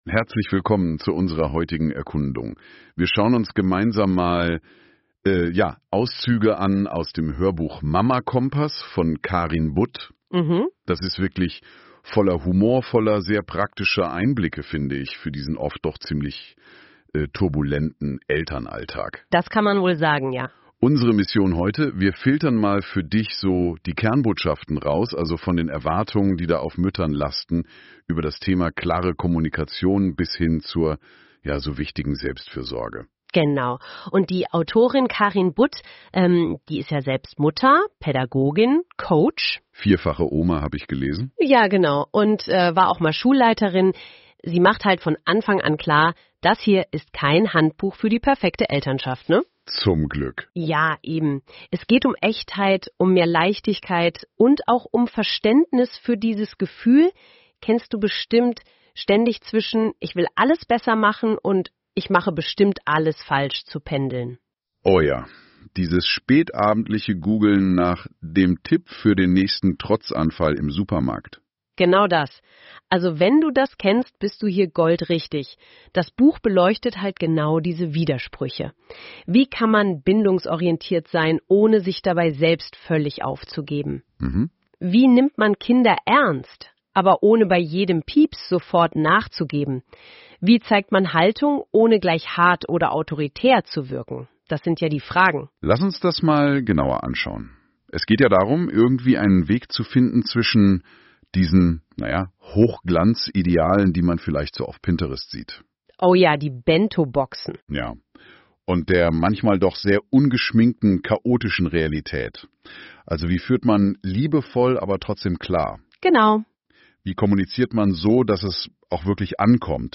Hörbuch-Teaser anhören